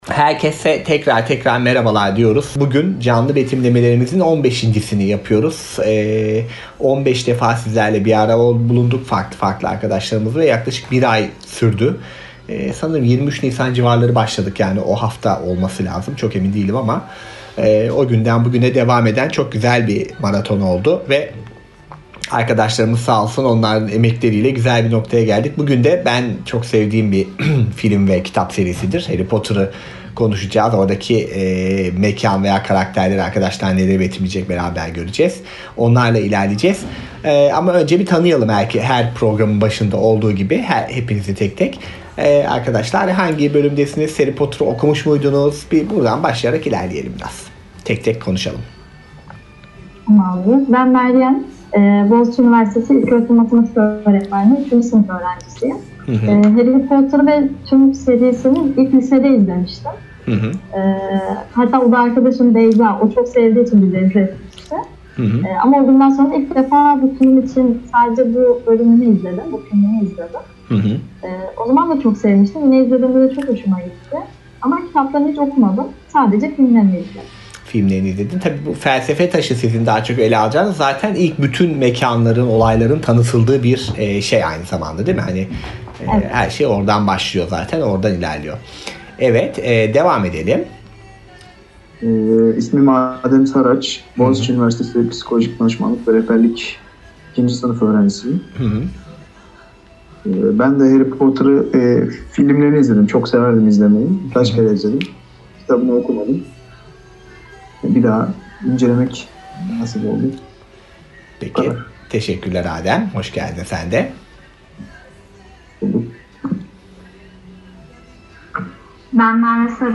Canlı Betimlemeler